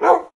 wolf
bark2.ogg